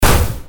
crash.ogg